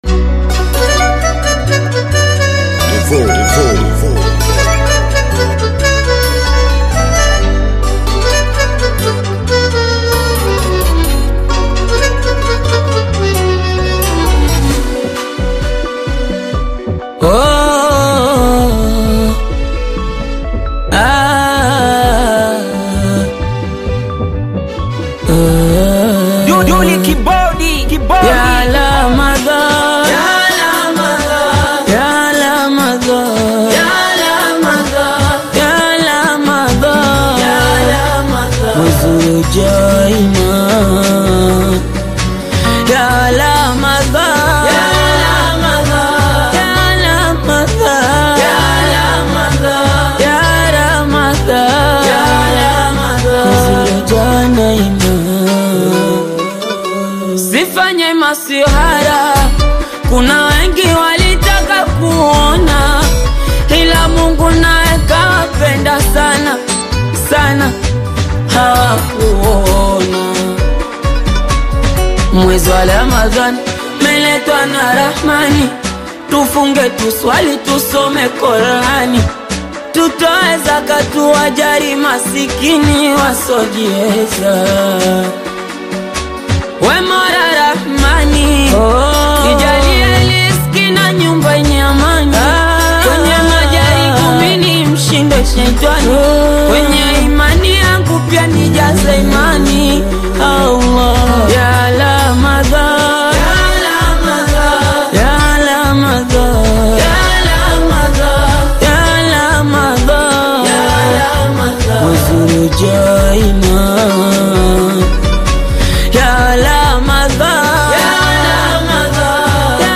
AudioQaswida
Tanzanian Singeli single